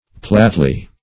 platly - definition of platly - synonyms, pronunciation, spelling from Free Dictionary Search Result for " platly" : The Collaborative International Dictionary of English v.0.48: Platly \Plat"ly\, a. Flatly.